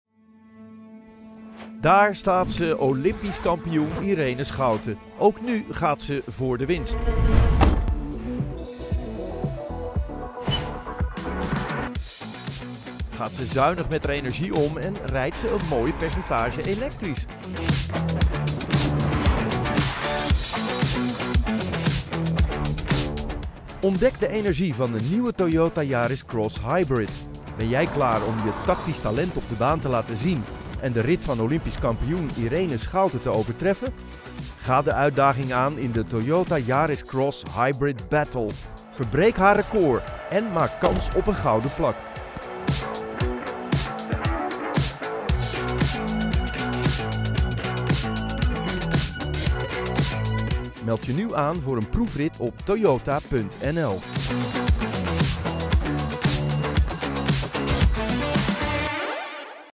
Corporate Videos
I have a friendly, knowledgeable, dynamic and/or emotional voice, but I can strike a lot of other tones as well.
- Soundproof home studio
Baritone